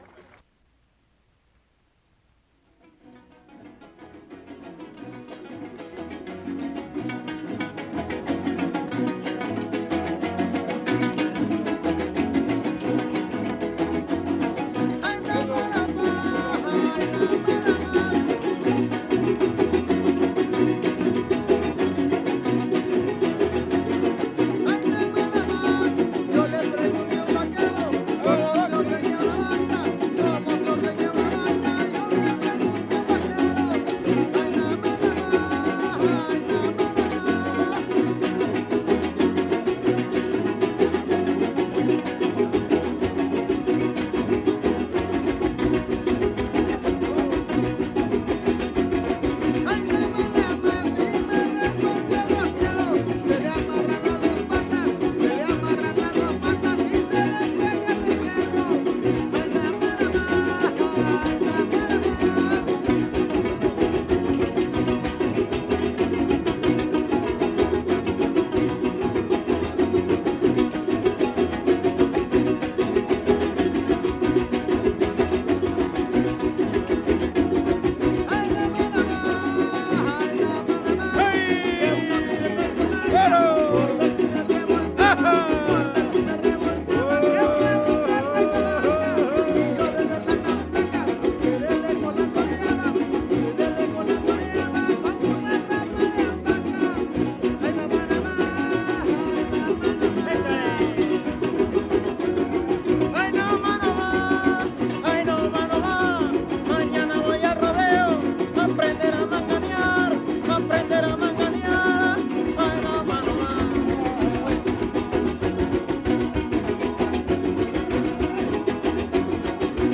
• Siquisirí (Grupo musical)
Fandango